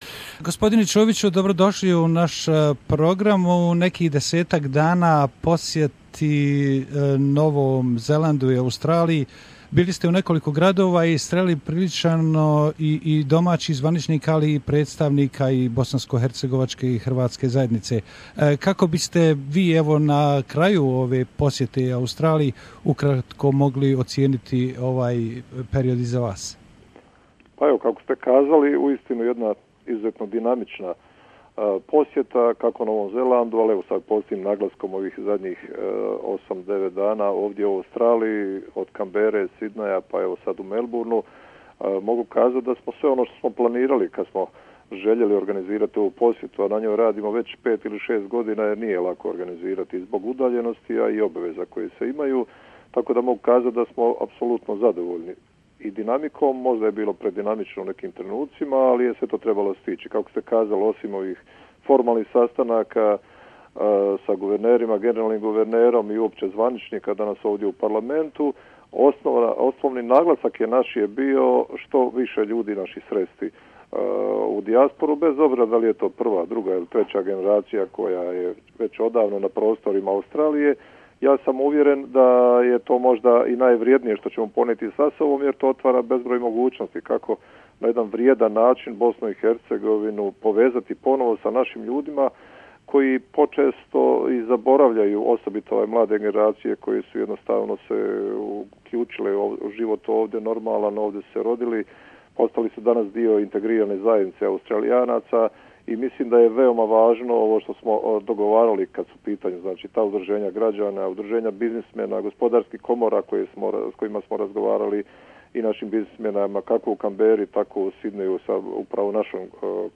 Prije nego je okončao posjetu Novom Zelandu i Australiji, predsjedavajući Predsjedništva Bosne i Hercegovine Dragan Čović je u razgovoru za naš radio program govorio o svojim susretima za zvaničnicima dvije zemlje, liderima i članovima zajednice kao i budućnosti odnosa bosanskohercegovačke dijaspore i prve domovine. Kakve su Čovićeve impresije nakon posjete, kako vidi izbore u Bosni i Hercegovini iduće godine?